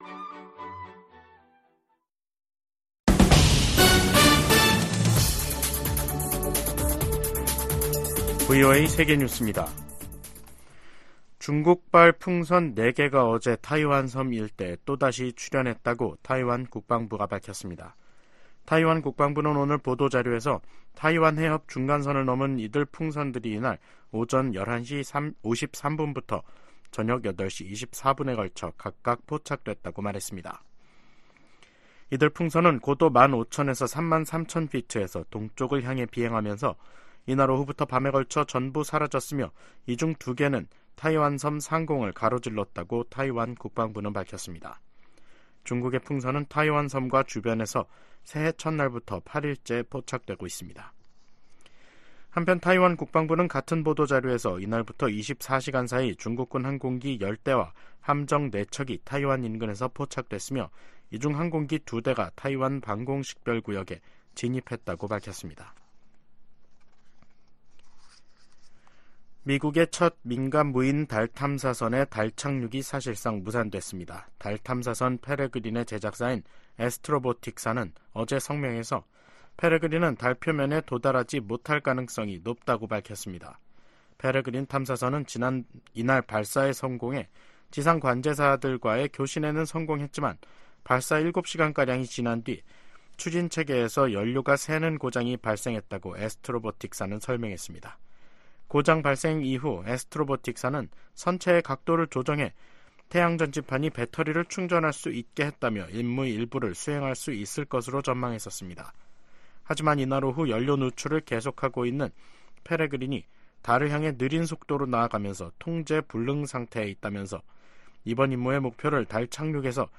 VOA 한국어 간판 뉴스 프로그램 '뉴스 투데이', 2024년 1월 9일 2부 방송입니다. 미국이 북한에 도발 자제와 외교적 해결을 촉구하는 한편 한국에 확고한 방위 공약을 거듭 확인했습니다. 북한의 포격 도발은 미한일 3국 협력 불만 표출과 총선을 앞둔 한국을 혼란시키려는 것으로 미 전문가들은 분석했습니다. 북한이 러시아에 첨단 단거리 미사일(SRBM)까지 넘긴 것으로 알려지면서 군사협력이 상당히 높은 수준에서 이뤄질 가능성이 제기되고 있습니다.